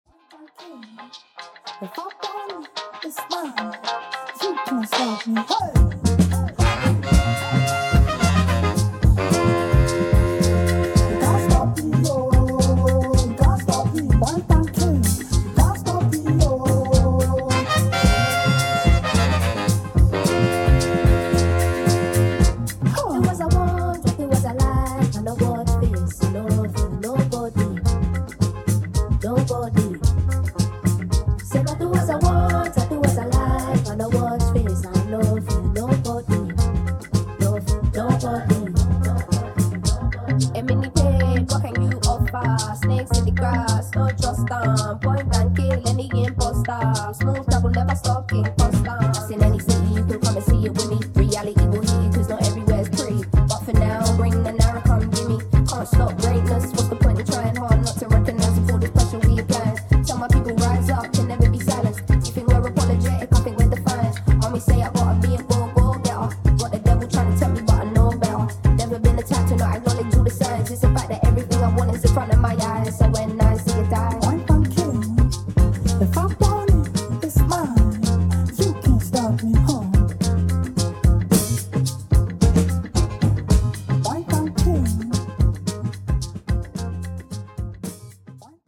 Hit-n-run blends and vinyl only amends.